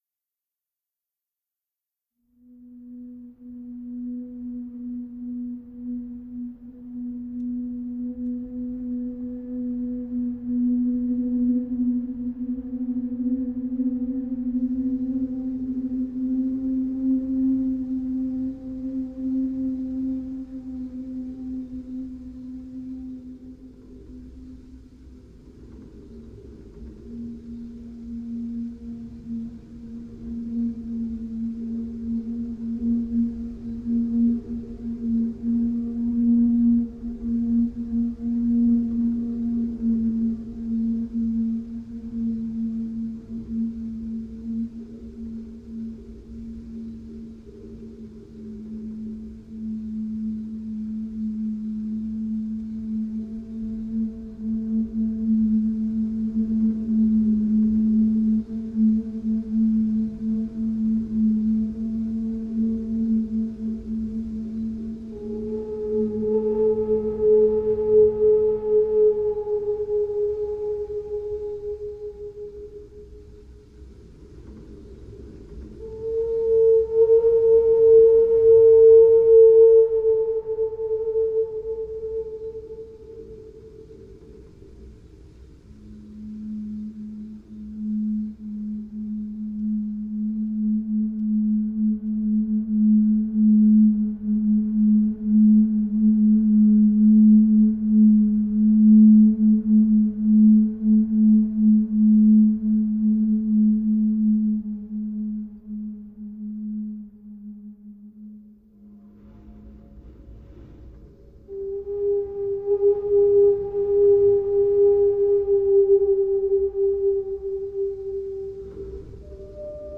Sphère éolienne en terre cuite ©
Aeolian Sphere made of terra cotta
Cette sphère produit un son particulier (uniquement la fréquence fondamentale) sous le souffle du vent.
Those sphere products a specific sound (only the fundamental frequency) when it is blowed by wind.
aeolian_ceramic.mp3